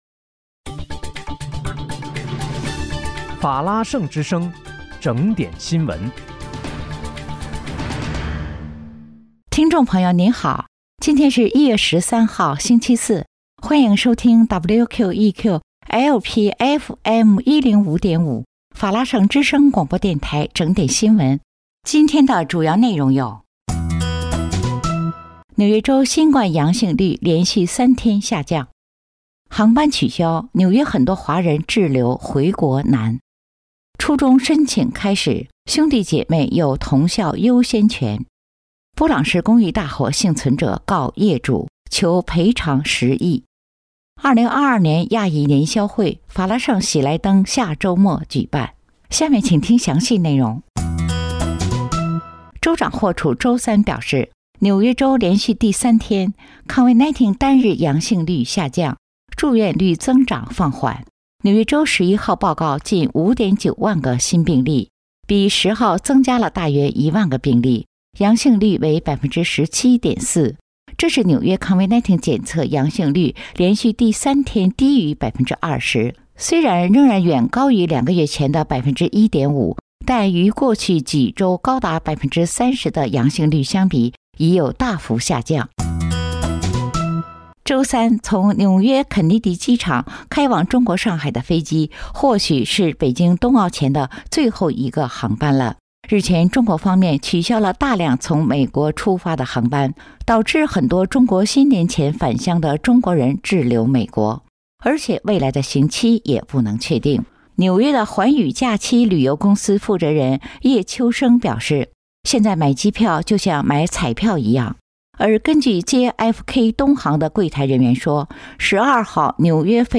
1月13日（星期四）纽约整点新闻